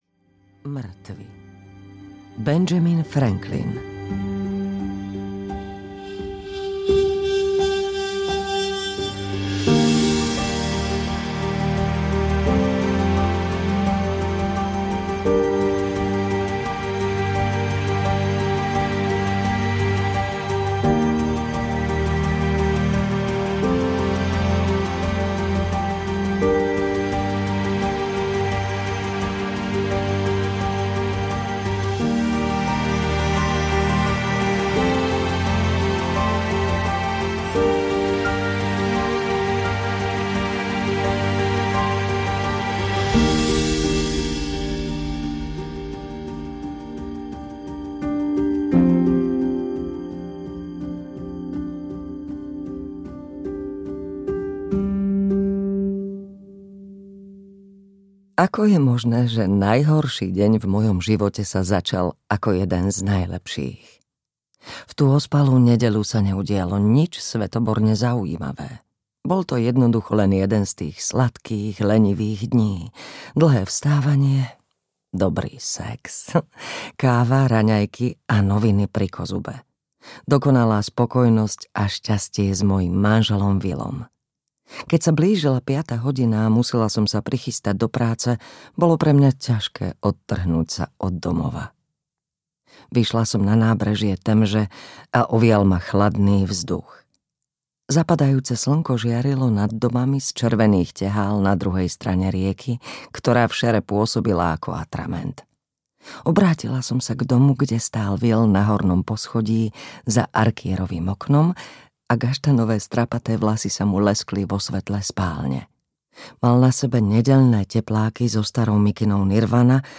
Ťaživé ticho audiokniha
Ukázka z knihy